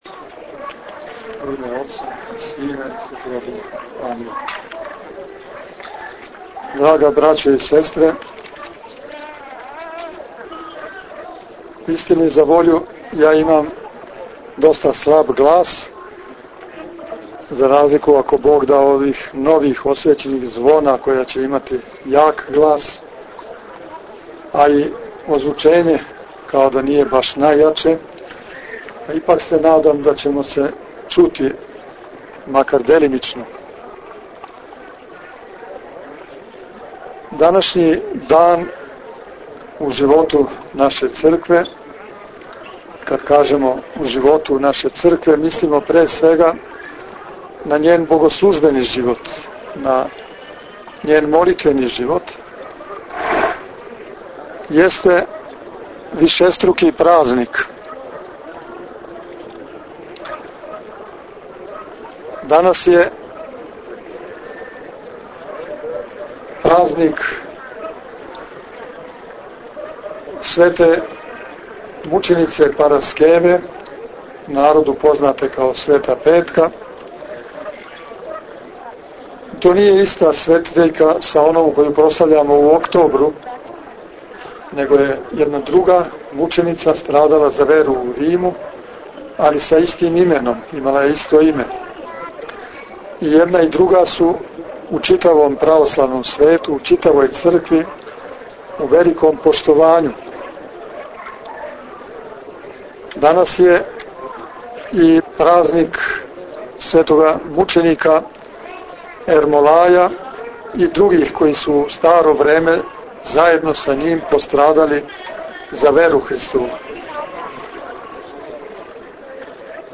У недељу, 8. августа текуће године,Његово Преосвештенство Епископ бачки Господин др Иринеј, уз саслужење неколико свештеника и мноштва верног народа, освештао је крст и звона за храм Светог Василија Острошког Чудотворца у Сиригу.